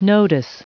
Prononciation du mot nodus en anglais (fichier audio)
Prononciation du mot : nodus